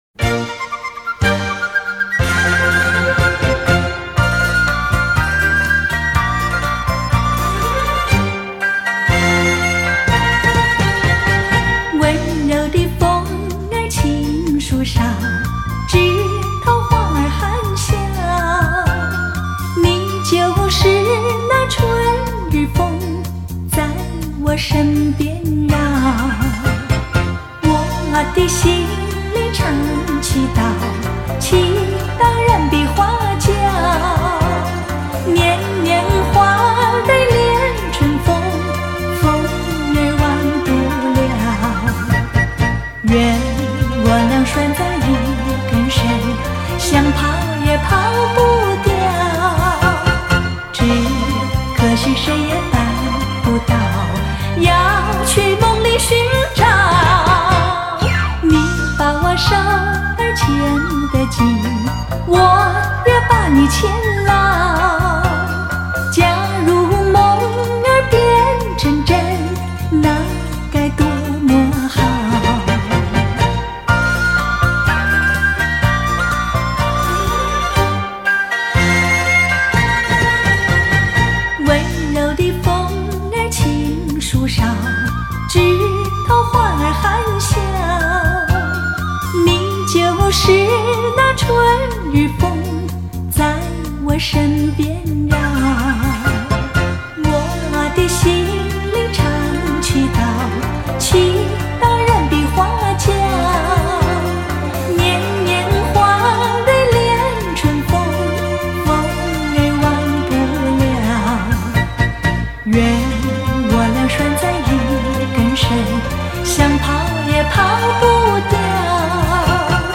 以当今至高规格音效处理
DXD重新编制